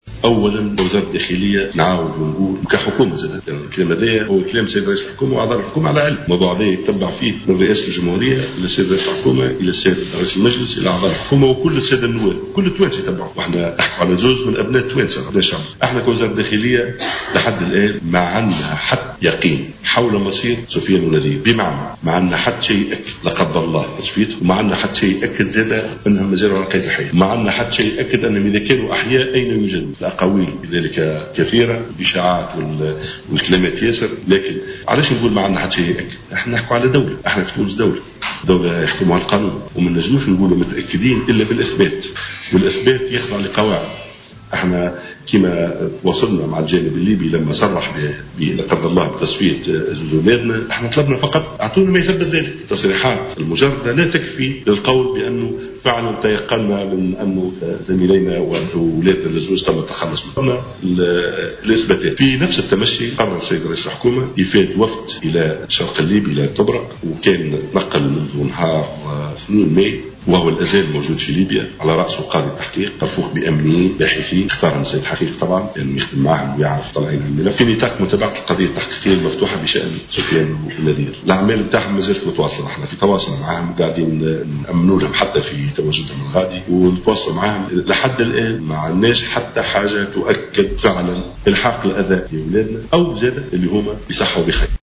قال وزير الداخلية ناجم الغرسلي اليوم الثلاثاء خلال ندوة صحفية